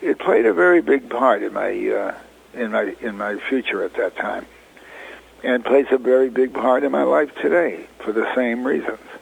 Lear returned to Iowa in 2014, and in a Radio Iowa interview, recalled how “Cold Turkey” came out the same year his show, “All in the Family” premiered on C-B-S. The ground-breaking sitcom dealt with controversial issues previously ignored in comedies.